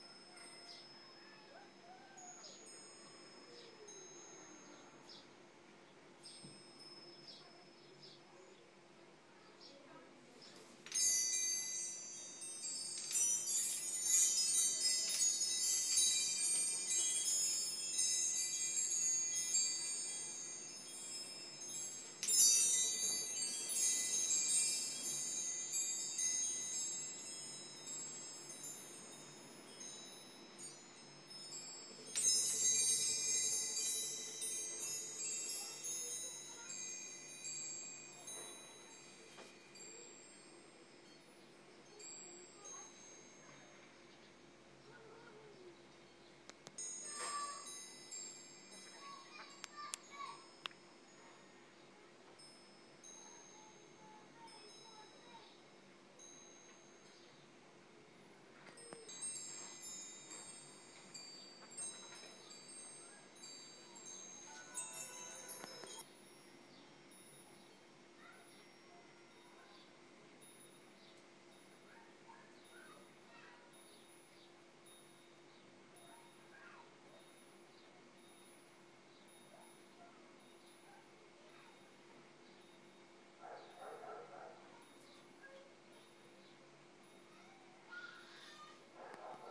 Windchime boo